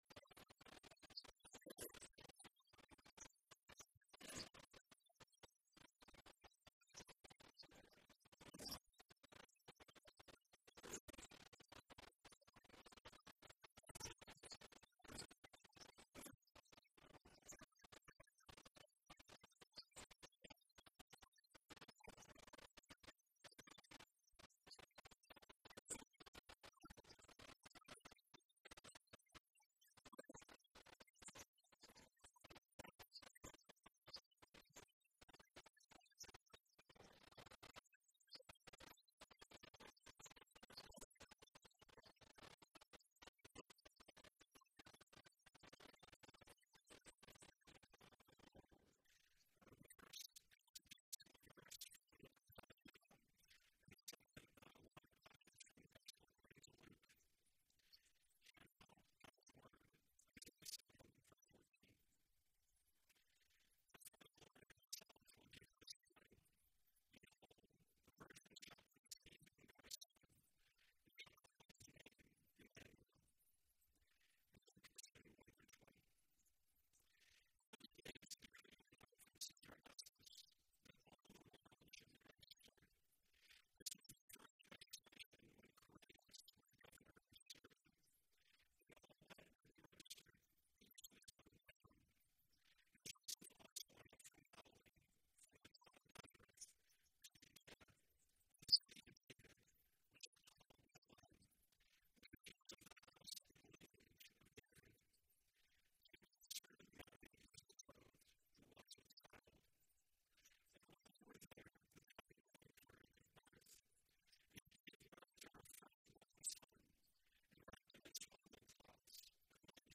Christmas Eve 2025 Passage: Isaiah 7:14; Luke 2:1-20 Service Type: Christmas Eve « Outward